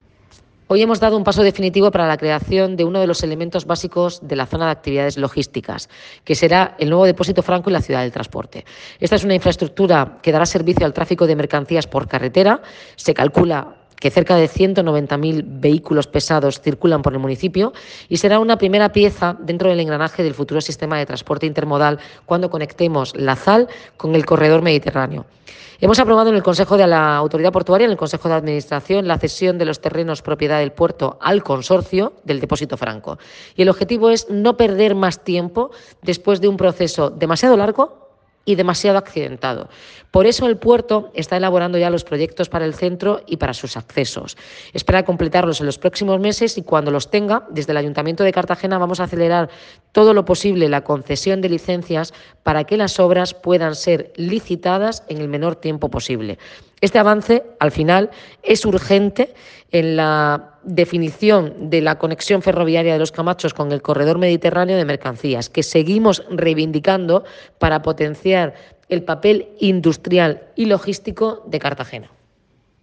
Enlace a Declaraciones de la alcaldesa, Noelia Arroyo.
La alcaldesa de Cartagena, Noelia Arroyo, ha valorado como "un paso definitivo" la aprobación de la cesión de los terrenos para crear la nueva Zona de Actividades Logísticas (ZAL) en el polígono de Los Camachos. La regidora ha participado este martes, 24 de octubre, en la reunión del Consejo de Administración de la Autoridad Portuaria de Cartagena.